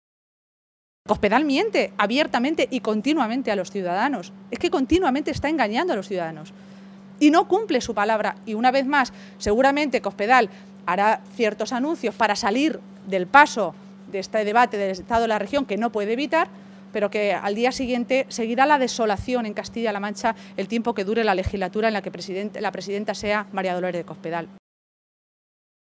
Maestre se pronunciaba de esta manera esta tarde, en Toledo, en una comparecencia previa a la reunión de la ejecutiva regional socialista en la que, según ha apuntado, se va a analizar el calendario de todos los procesos internos que debe afrontar a partir de ahora los socialistas.